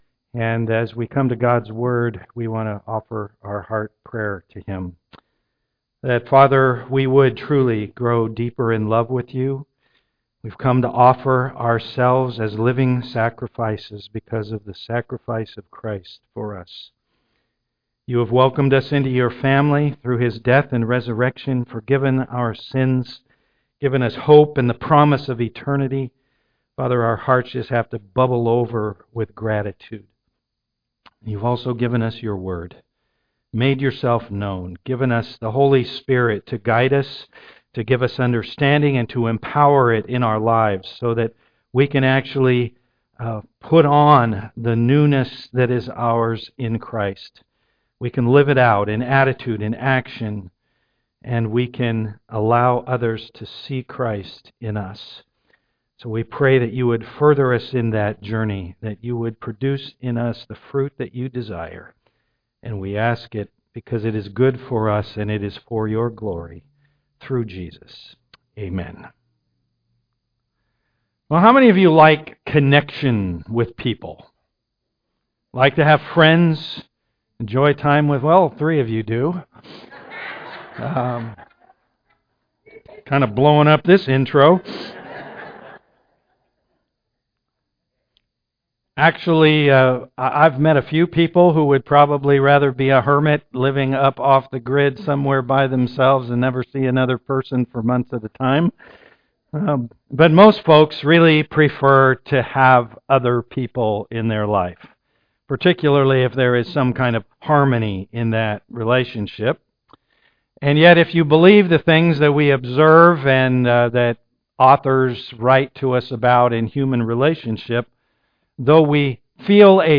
John 15:1-17 Service Type: am worship Last words matter.